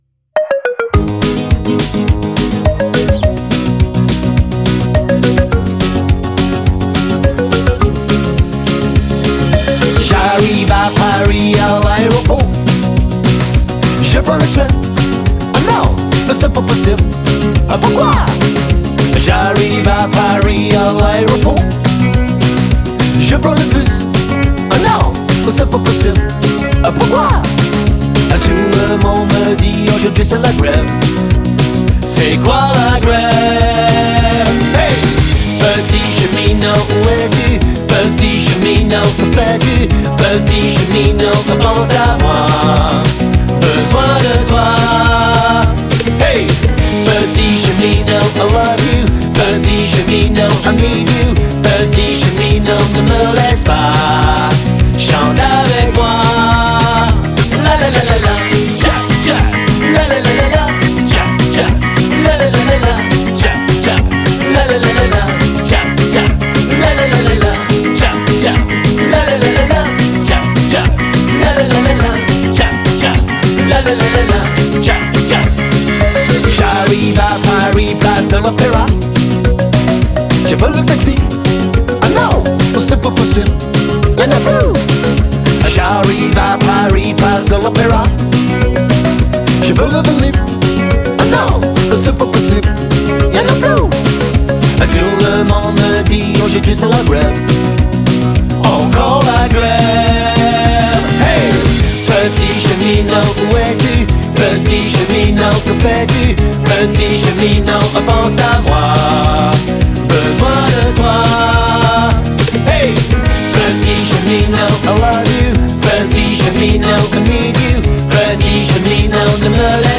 avé l’accent anglais